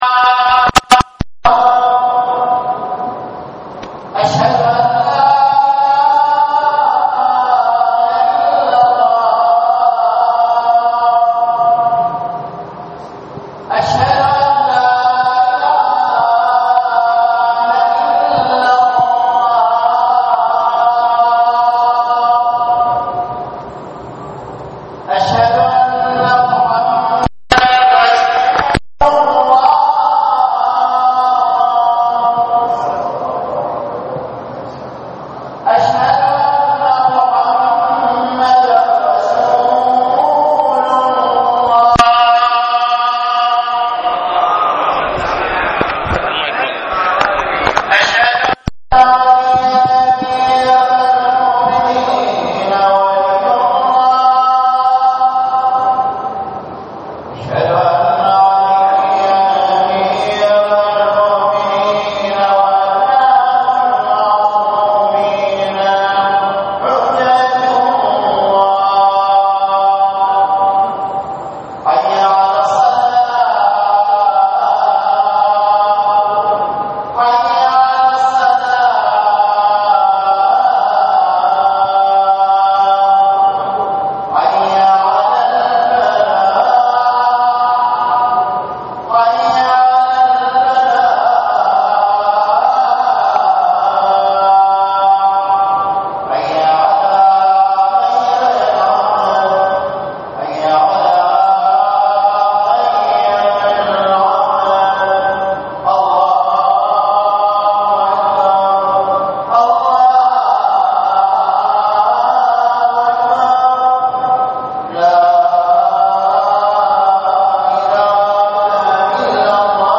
للاستماع الى خطبة الجمعة الرجاء اضغط هنا